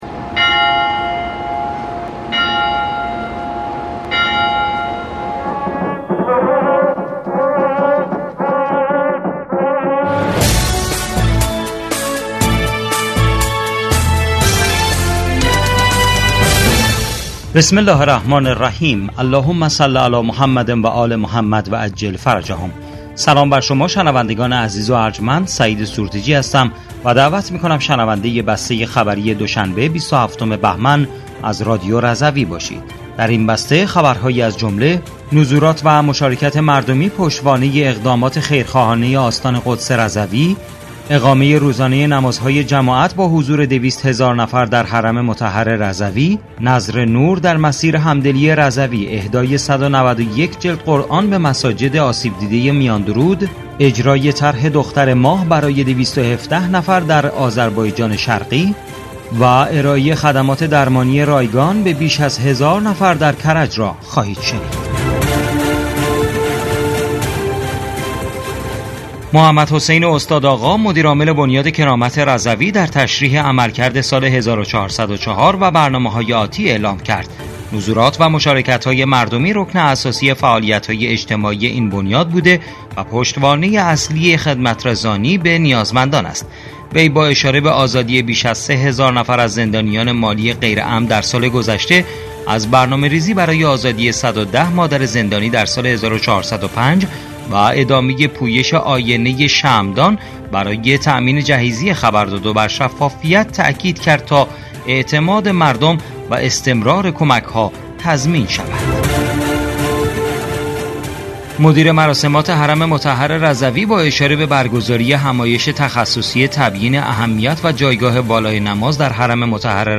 بسته خبری ۲۷ بهمن ۱۴۰۴ رادیو رضوی؛